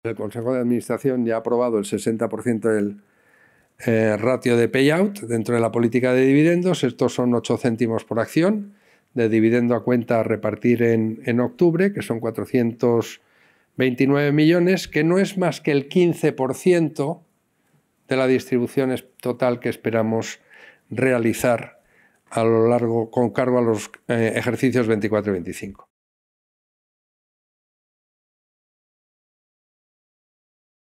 Material audiovisual de la rueda de prensa